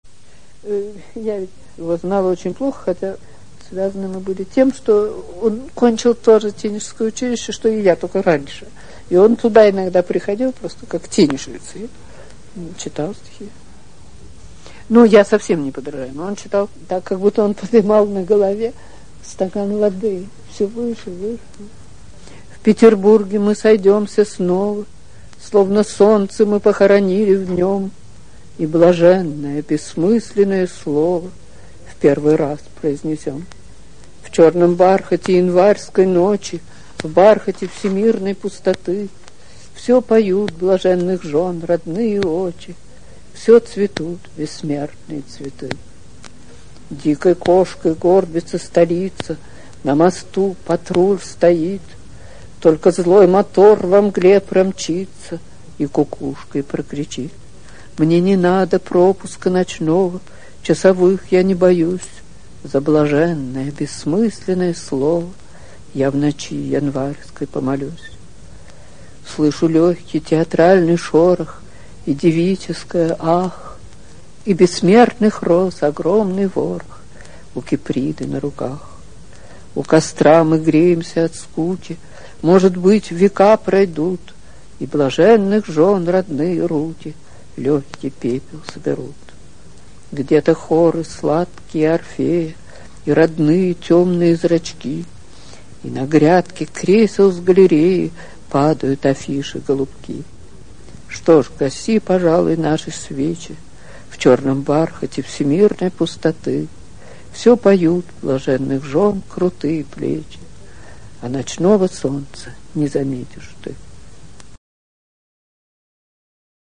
3. «Лидия Чуковская (читает) – “В Петербурге мы сойдемся снова…” (Мандельштам)» /
lidiya-chukovskaya-chitaet-v-peterburge-my-sojdemsya-snova-mandelshtam